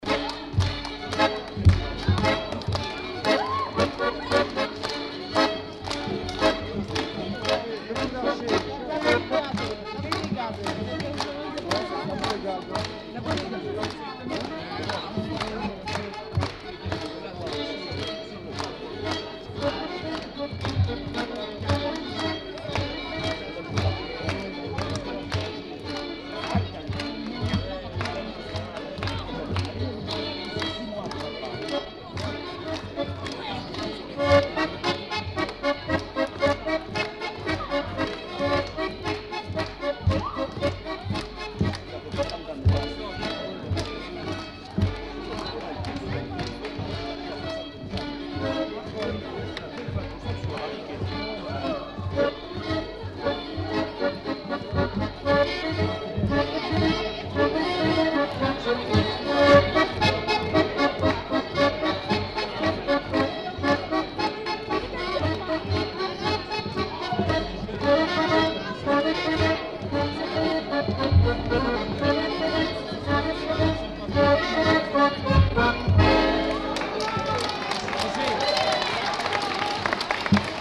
Rondeau
Aire culturelle : Gascogne
Lieu : Samatan
Genre : morceau instrumental
Instrument de musique : violon ; accordéon chromatique
Danse : rondeau